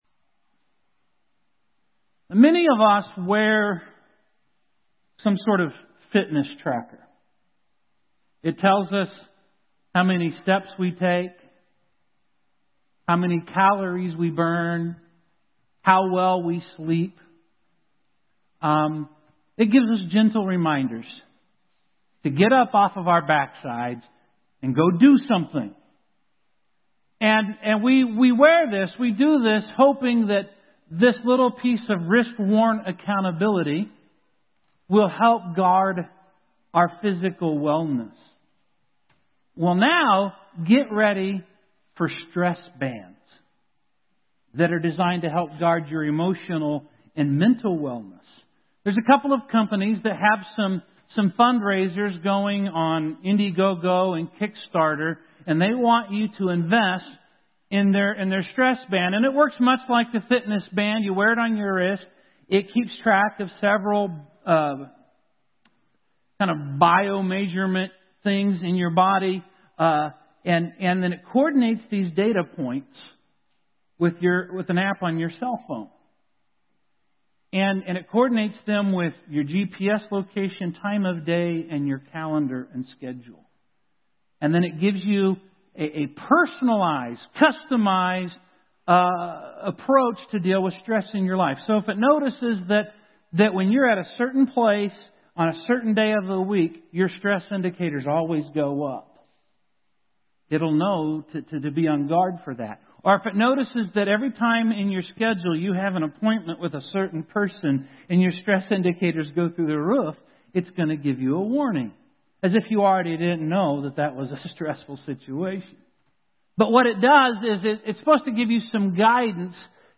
How can he be both? This sermon will look at how the shepherd literally lays his life down for the sheep to protect them from danger.